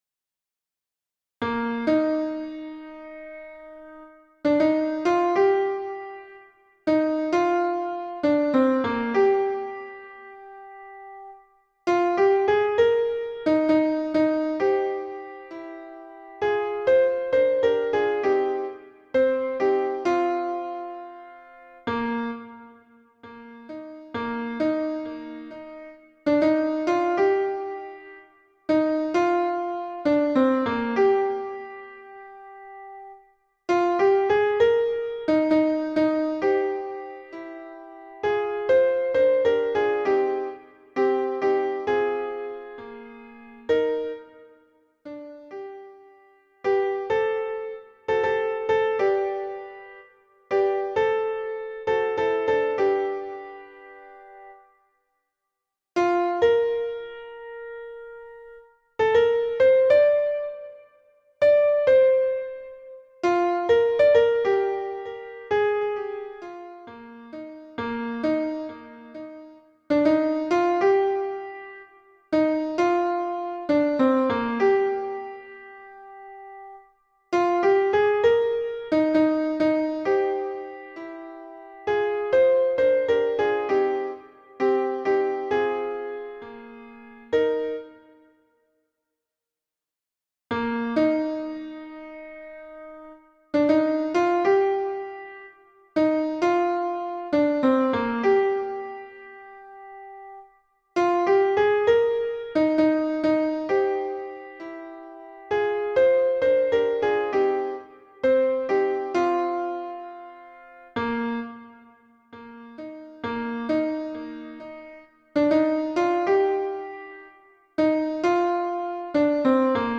Hommes et autres voix en arrière-plan